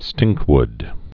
(stĭngkwd)